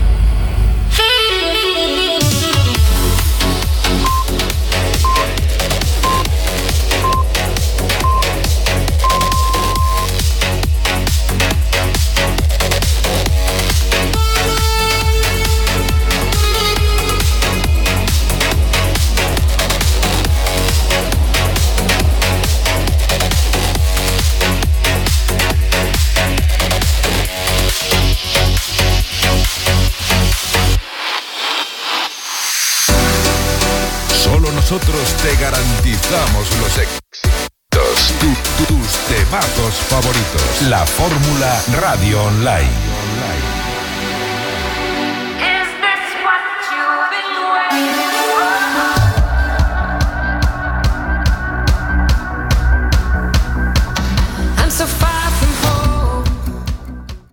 Tema musical, identificació de la ràdio i tema musical